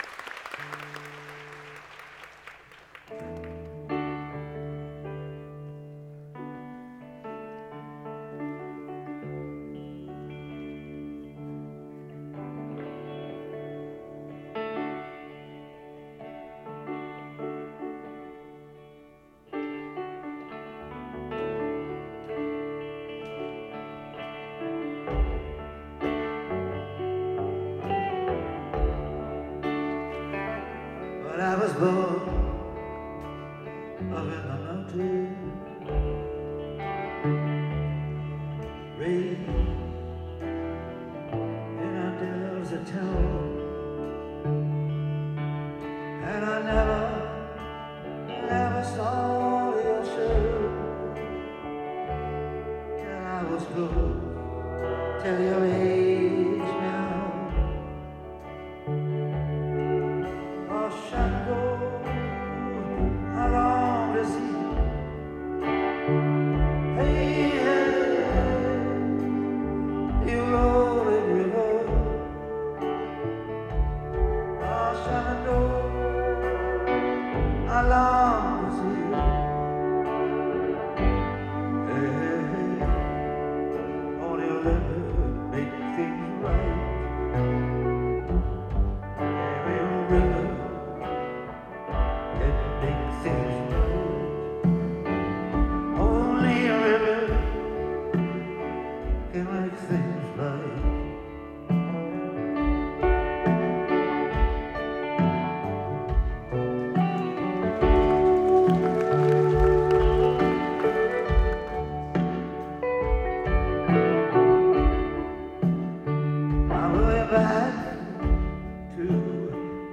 for the first time ever last night in Nagoya.